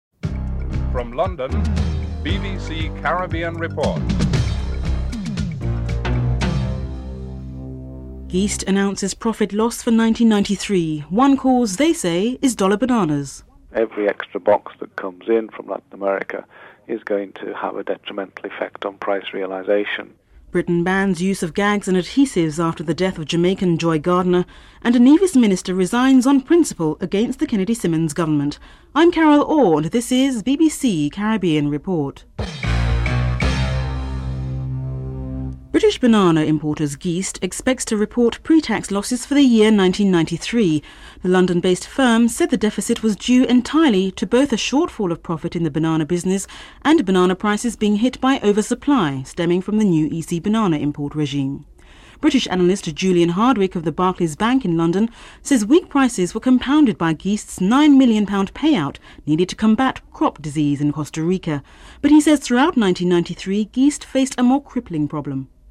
9. Theme song (14:43-14:58)